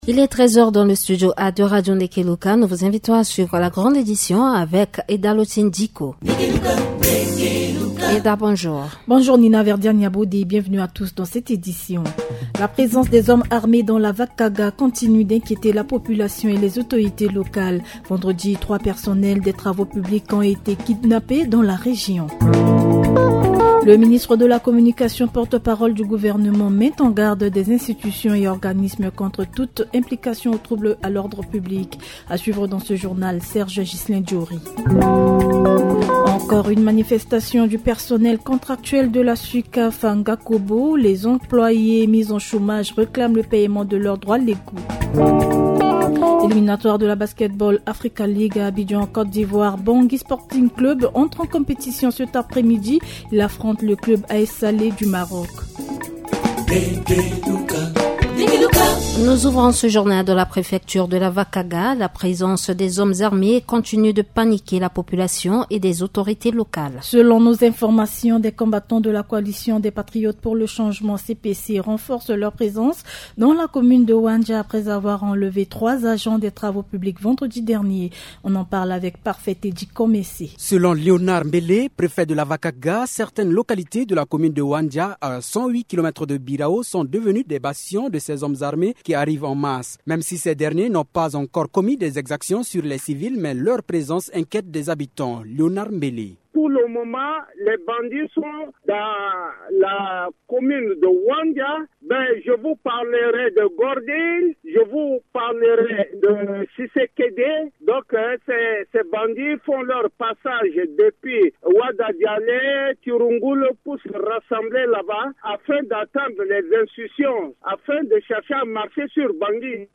Journal en français